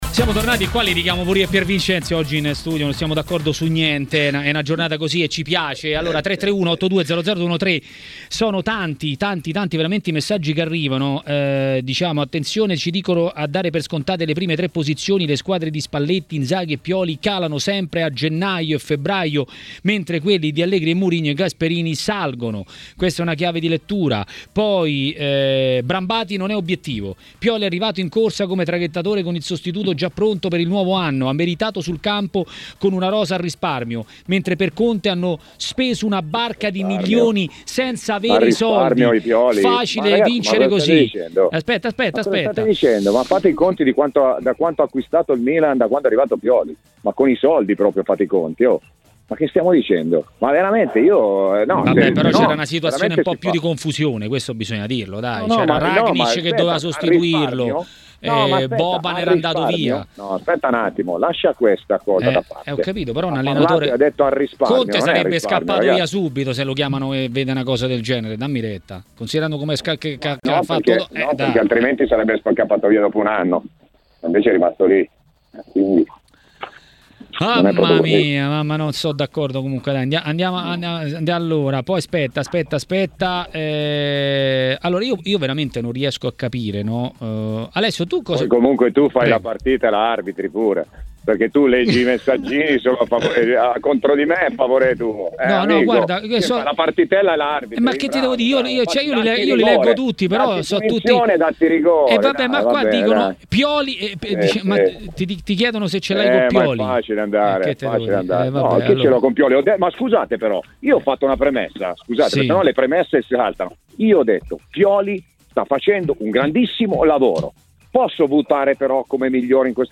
A Maracanà, nel pomeriggio di TMW Radio, è arrivato il momento dell'ex calciatore e tecnico Alessio Tacchinardi.